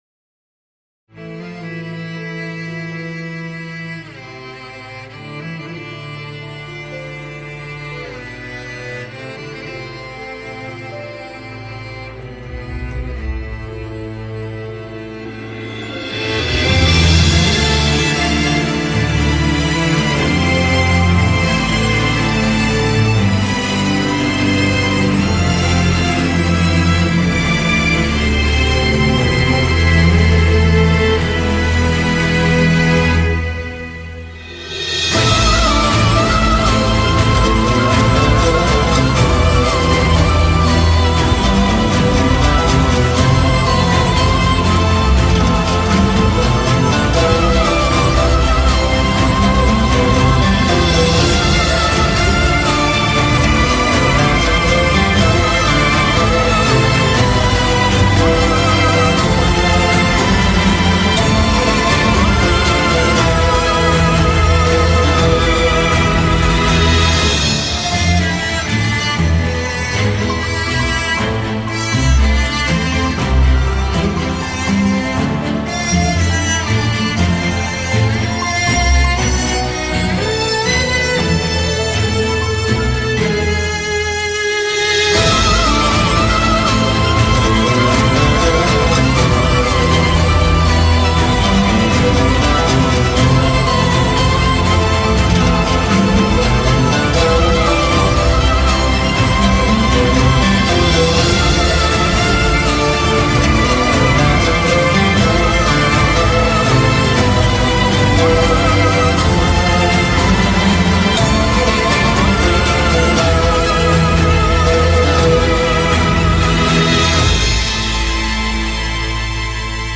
tema dizi müziği, duygusal hüzünlü üzgün fon müzik.